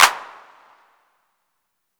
Clap11.Wav